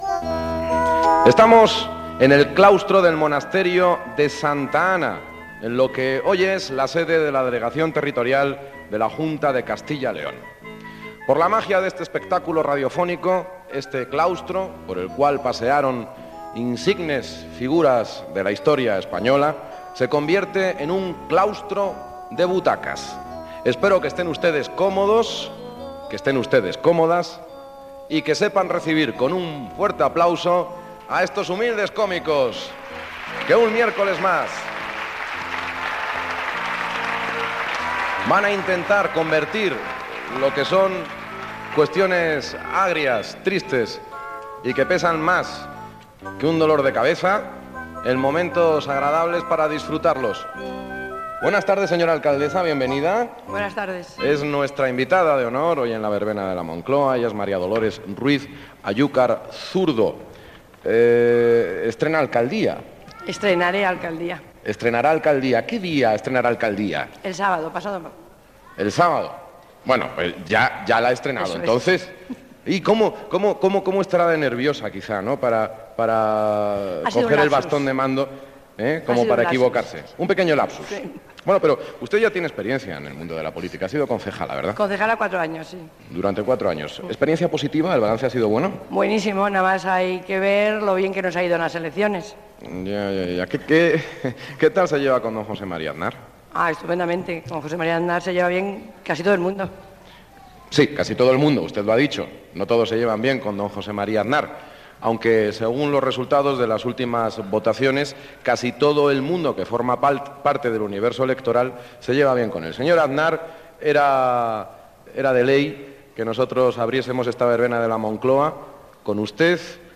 caec55040f29657df4856bef99d4f611e3c32d1c.mp3 Títol Cadena SER Emissora Ràdio Barcelona Cadena SER Titularitat Privada estatal Nom programa La verbena de la Moncloa Descripció Programa fet des del claustre de Santa Ana d'Ávila.
Gènere radiofònic Entreteniment